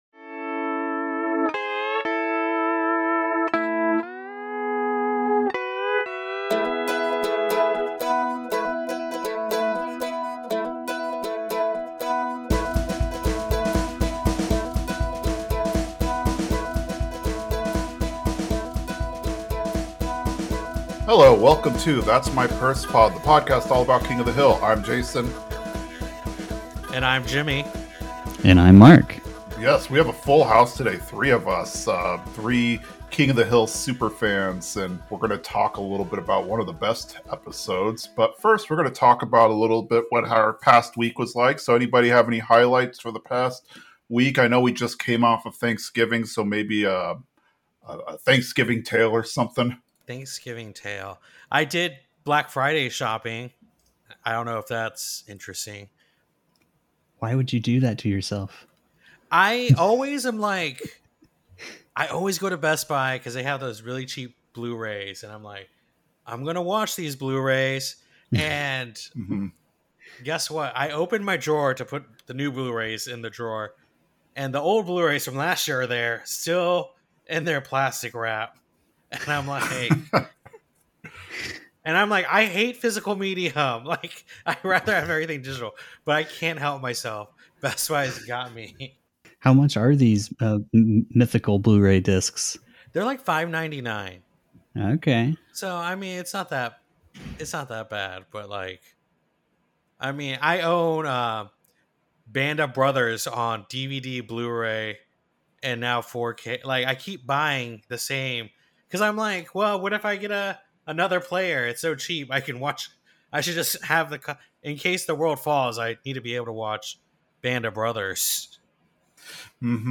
it's a party of three. We cover a post Thanksgiving episode where Cotton ruins the holiday and insults Hank's mother and his mower.